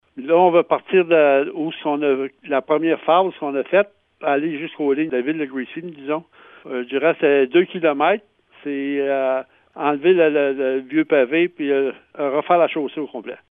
Le maire, Gary Lachapelle, espère que sa municipalité puisse obtenir une aide financière couvrant au moins 70% des coûts des travaux :